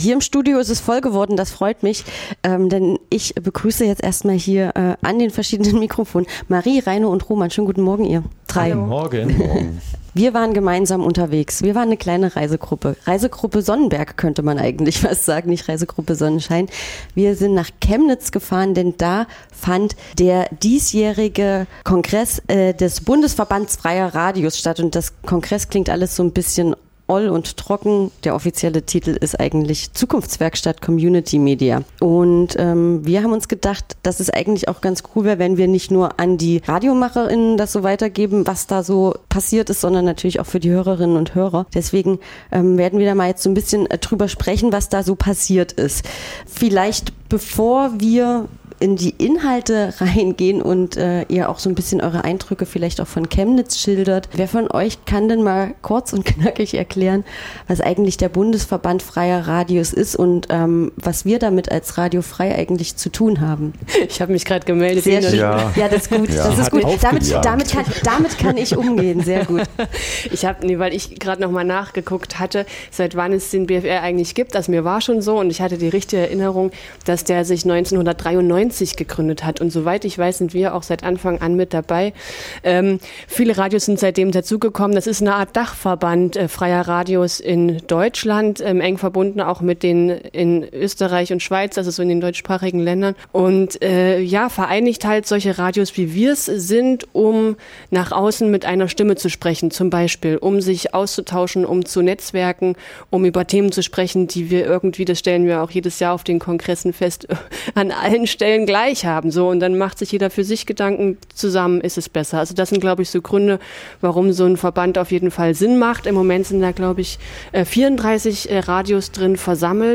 Auch Sendungsmacher:innen von Radio F.R.E.I. waren vor Ort und berichten von ihren Eindr�cken, Gespr�chen und den Themen, die die freie Radiolandschaft derzeit bewegen.